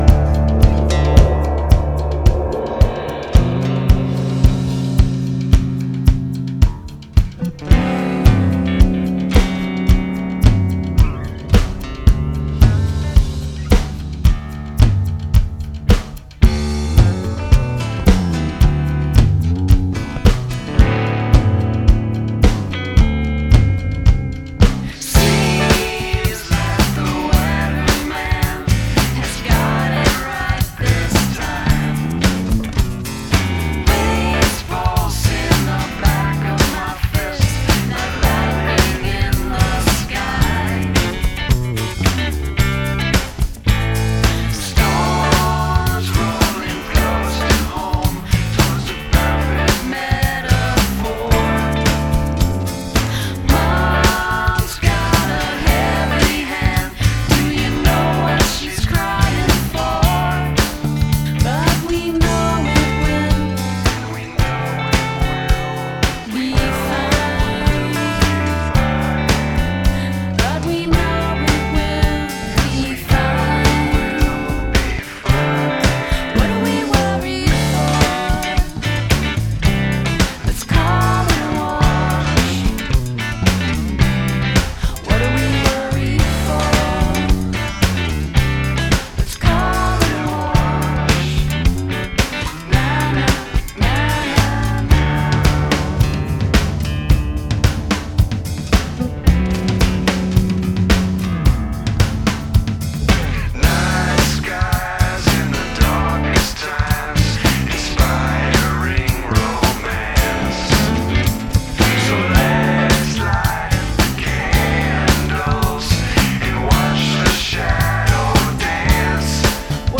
Interview
play songs of their not-yet-released album